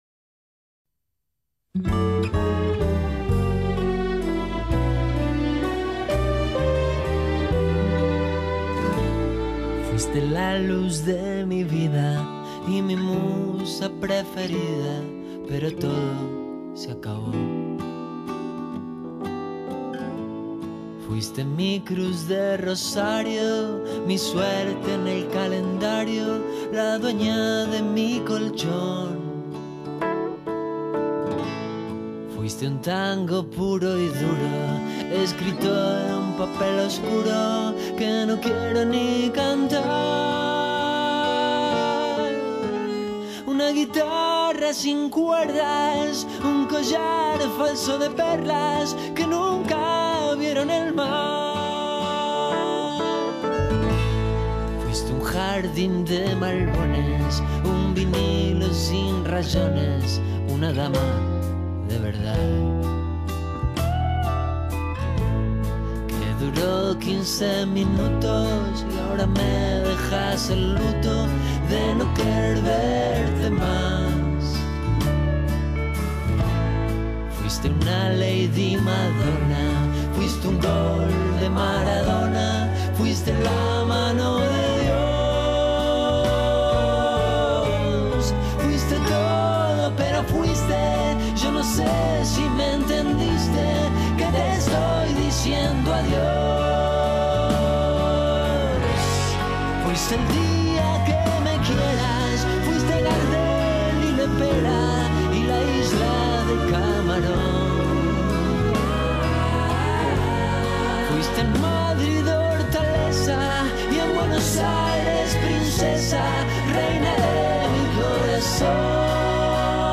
El músico argentino